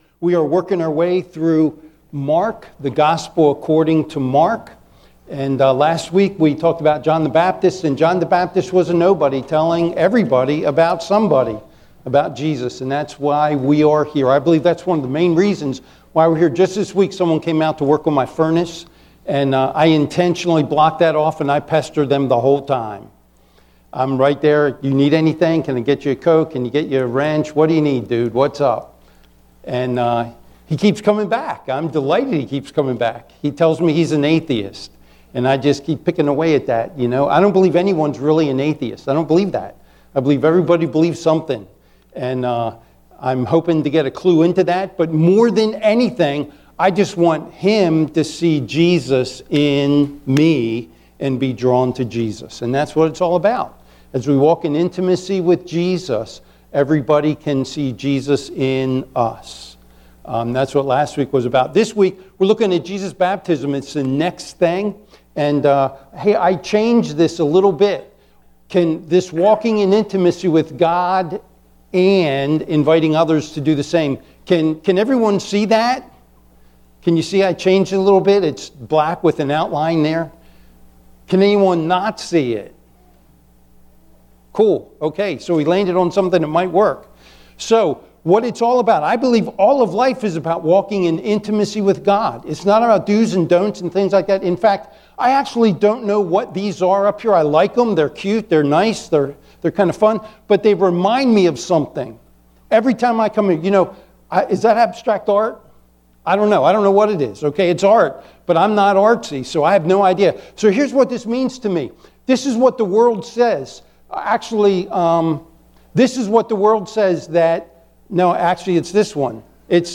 Sermons | Buckhannon Alliance Church